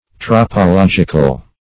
Tropologic \Trop`o*log"ic\, Tropological \Trop`o*log"ic*al\, a.